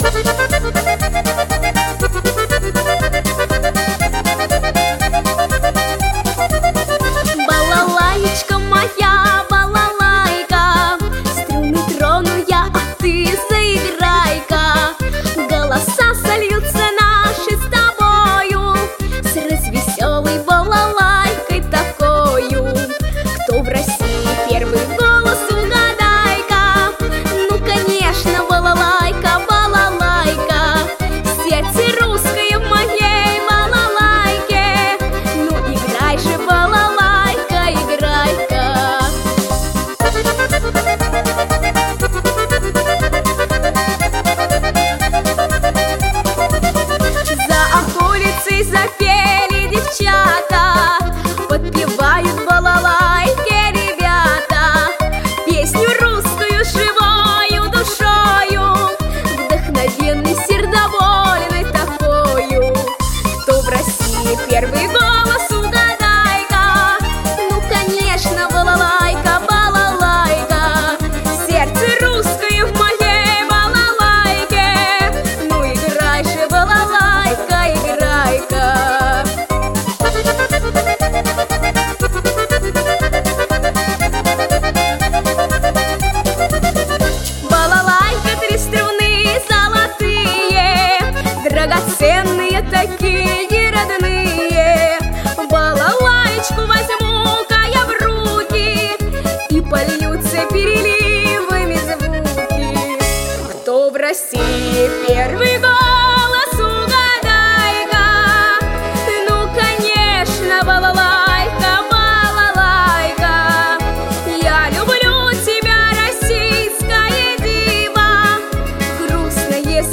• Качество: Хорошее
• Жанр: Детские песни
народный мотив